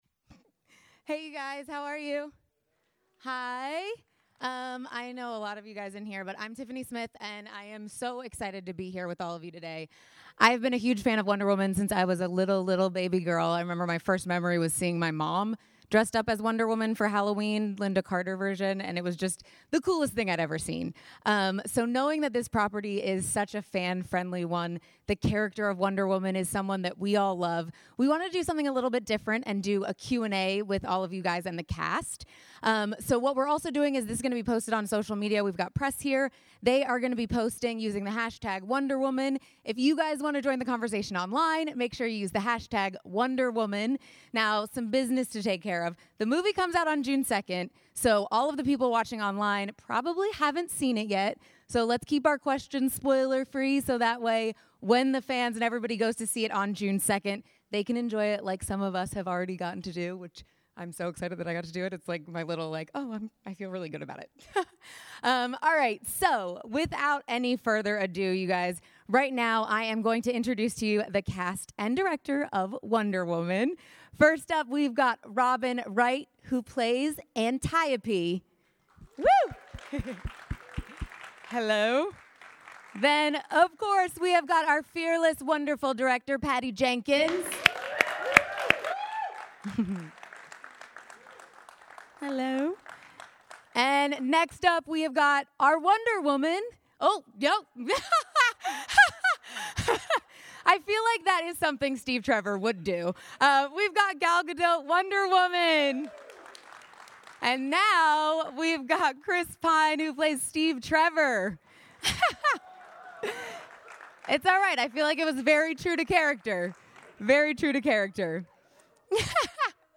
Wonder Woman Press Conference Audio Featuring Gal Gadot - Comix Asylum